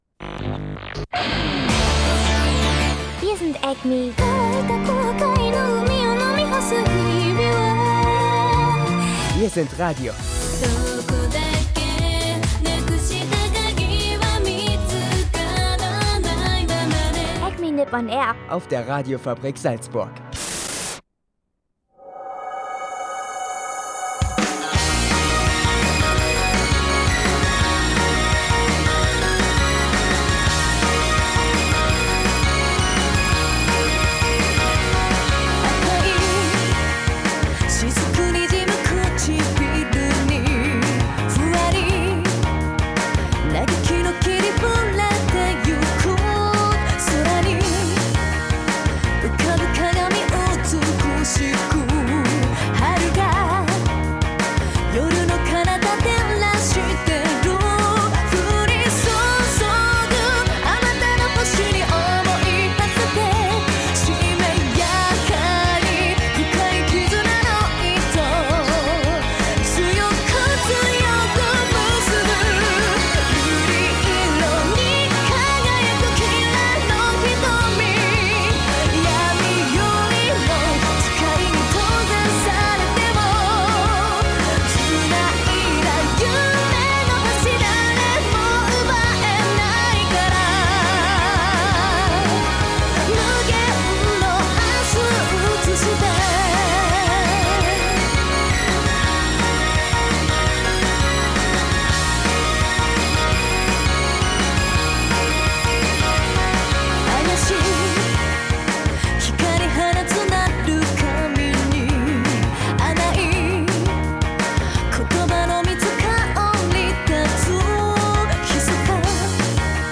Eine ganz normale Sendung diese Woche, mit Animemusik, Animetipp, Japan News und Studiogästen.